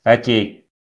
go-speech - TTS service